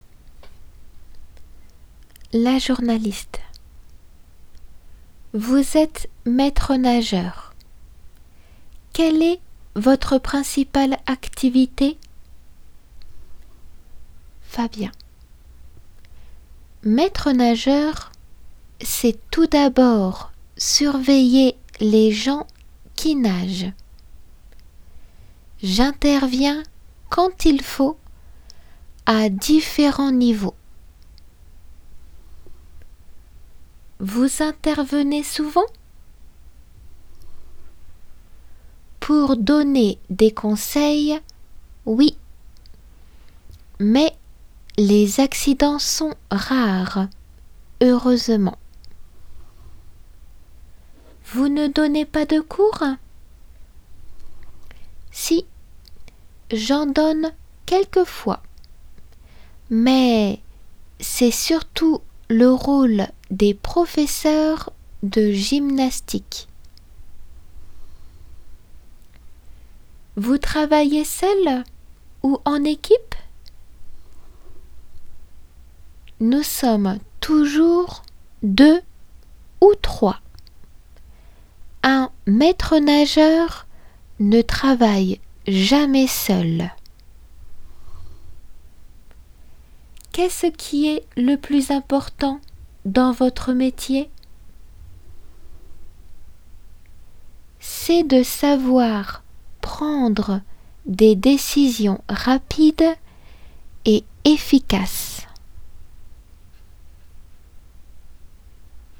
先ず読まれる本文の２人の会話をよく理解して聞けることです。